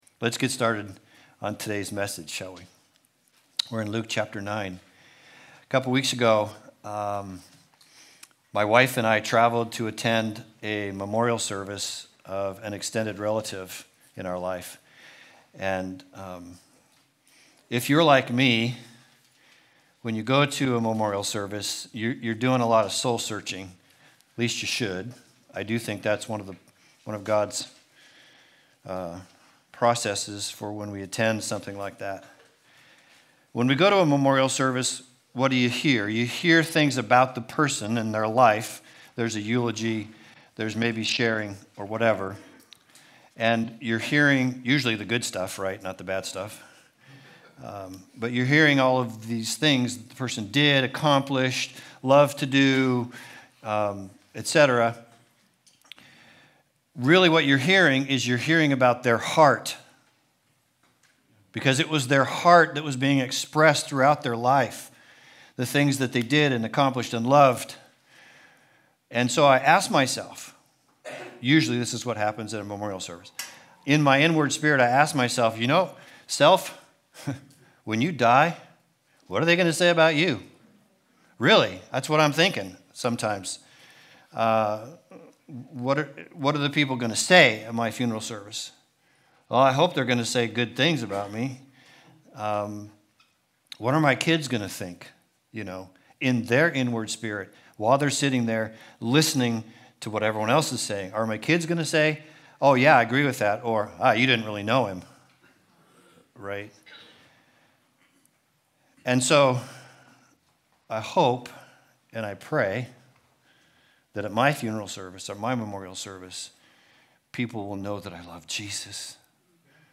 Passage: Luke 9:51-62 Service Type: Sunday Service